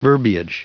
Prononciation du mot verbiage en anglais (fichier audio)